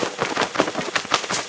bat